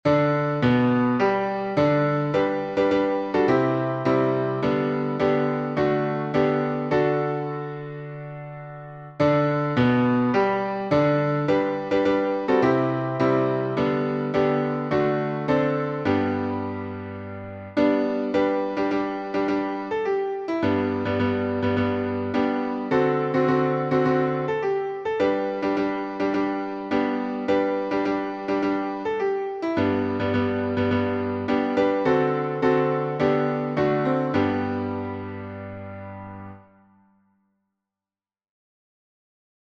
Key signature: G major (1 sharp) Time signature: 4/4 Meter